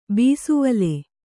♪ bīsuvale